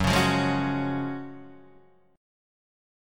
F# Major 7th Suspended 2nd